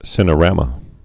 (sĭnə-rămə, -rämə)